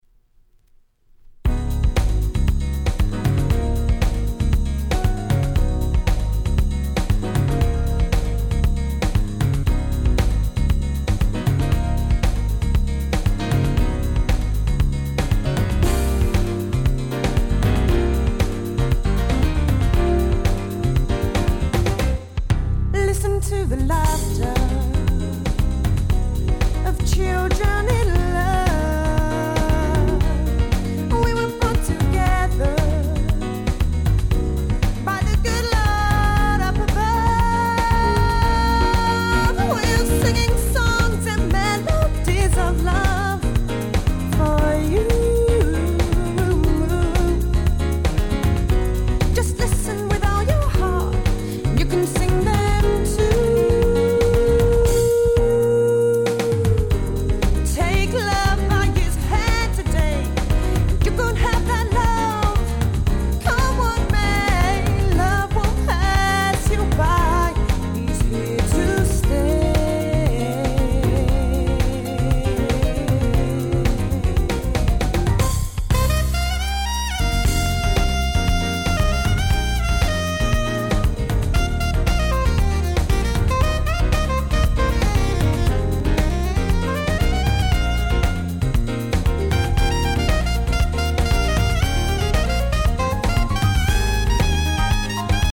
残念な事に、このレコード、全ての盤にてA-1後半にプレスミスによる音飛び箇所がございます。